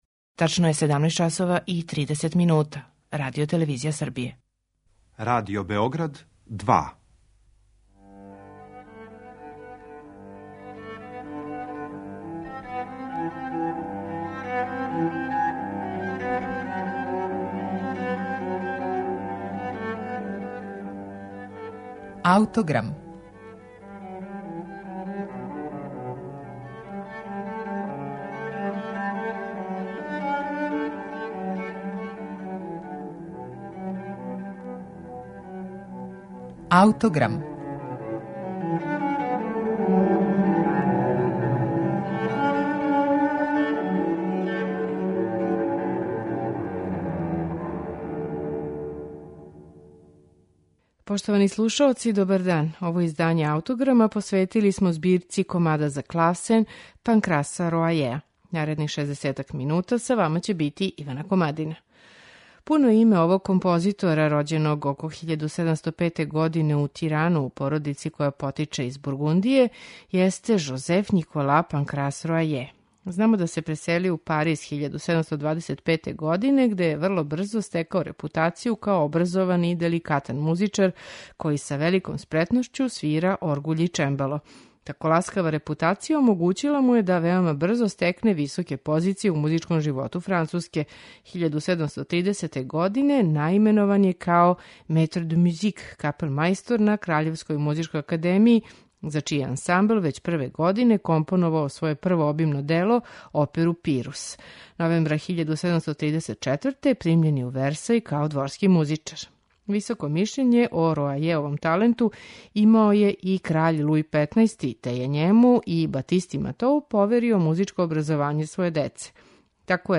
За данашњи Аутограм који смо посветили овој Роајевој збирци, издвојили смо 14 комада које ћете слушати у интерпретацији Кристофа Русеа, који свира на чембалу израђеном 1751. године у радионици Хајнриха Хермша.